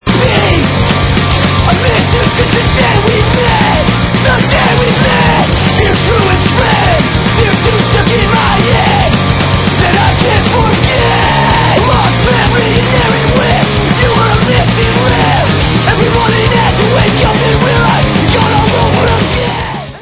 Dark, yet melodic hardcore